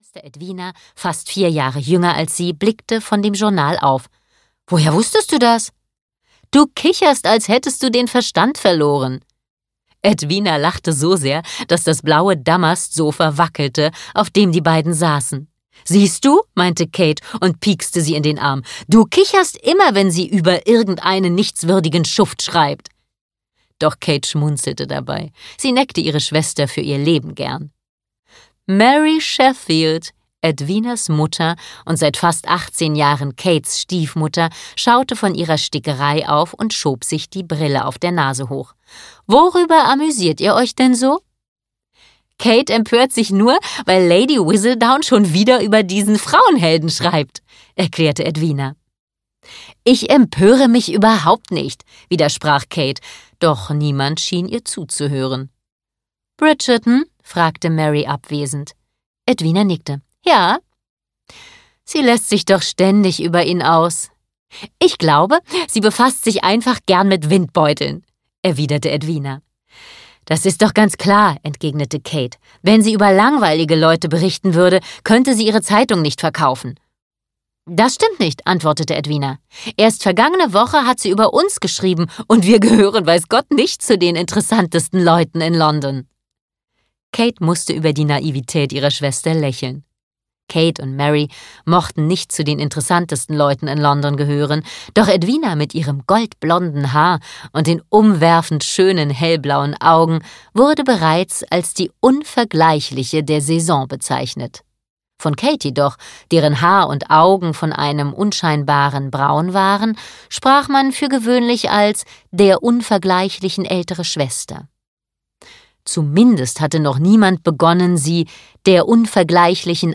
Bridgerton - Wie bezaubert man einen Viscount? (DE) audiokniha
Ukázka z knihy